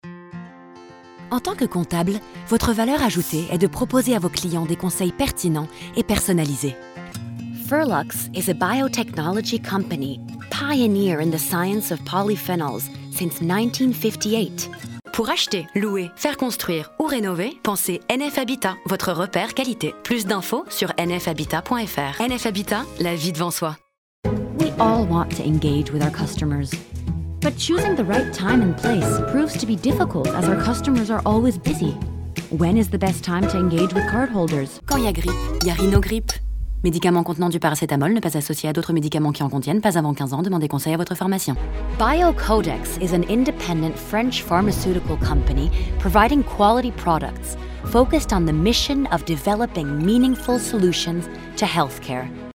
Voix off
Bande démo voix bilingue